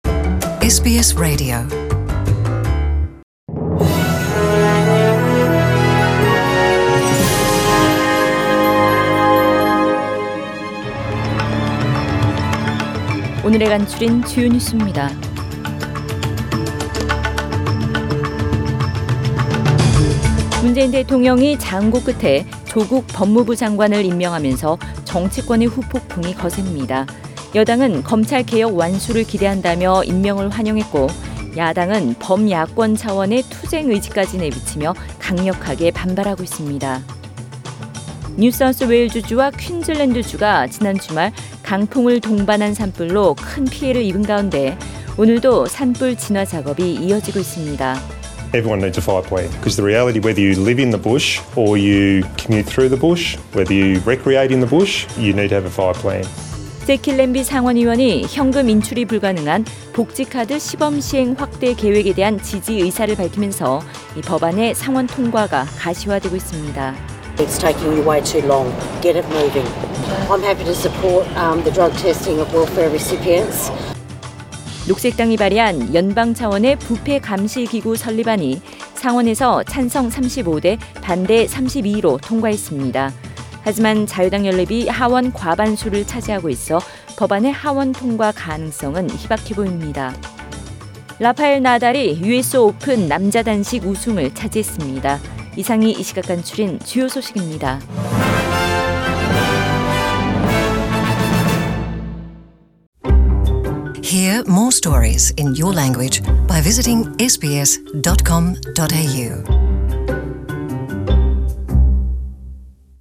SBS 한국어 뉴스 간추린 주요 소식 – 9월 9일 월요일